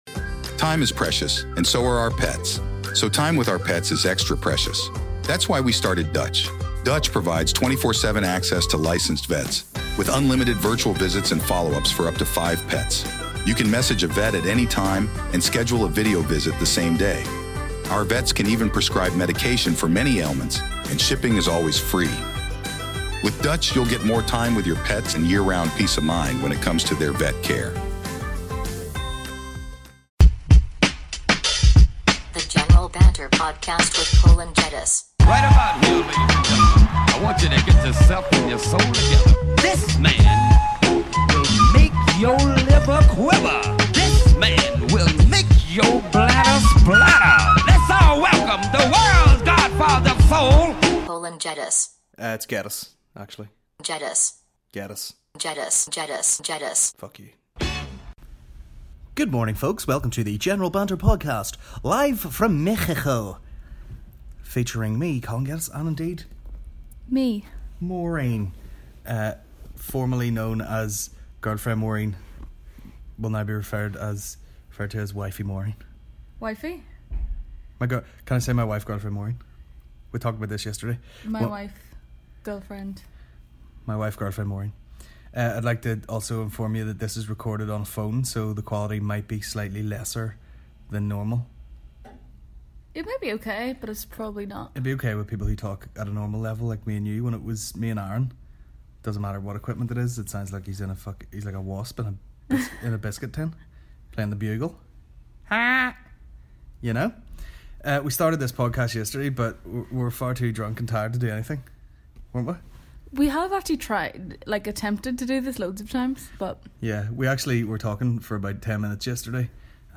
Monday 21st May 2018 General Banter Podcast General Banter Podcast Comedy 4.8 • 1.1K Ratings 🗓 21 May 2018 ⏱ 87 minutes 🔗 Recording | iTunes | RSS 🧾 Download transcript Summary This week - Our Stupid Wedding.